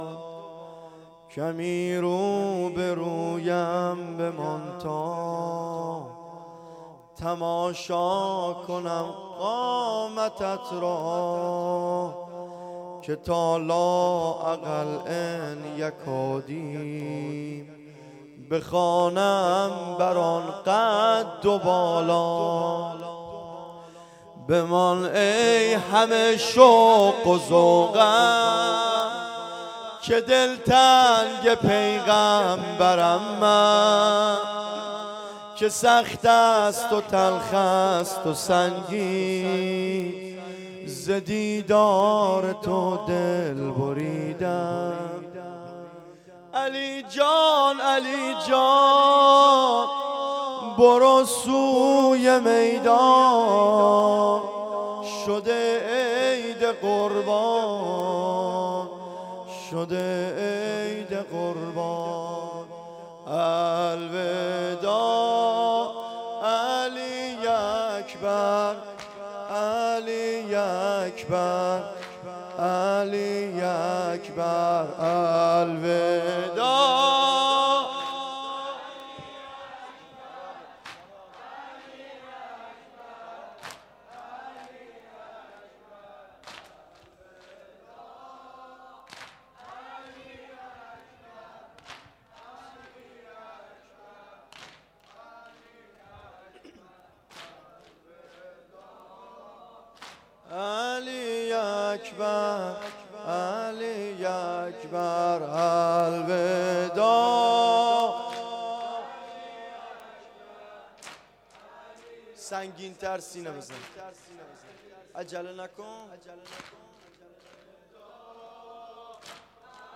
شب هشتم ماه محرم